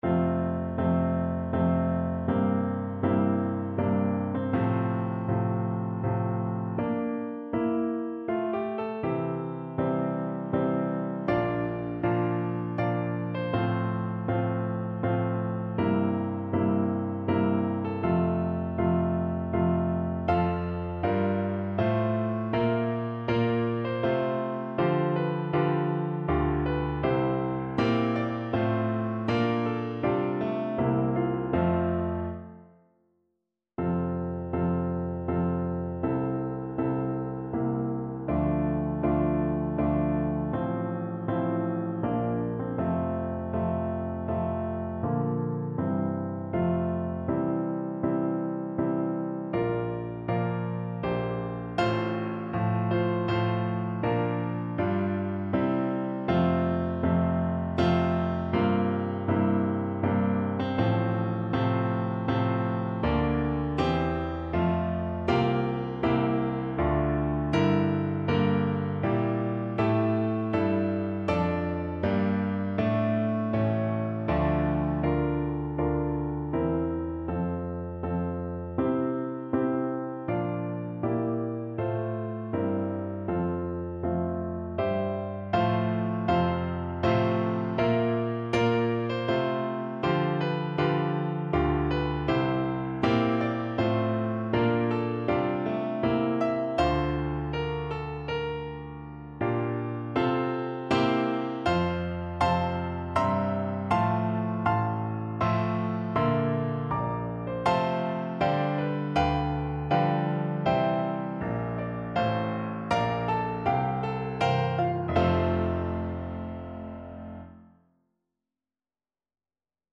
Andante grandioso
3/4 (View more 3/4 Music)
Classical (View more Classical Soprano Voice Music)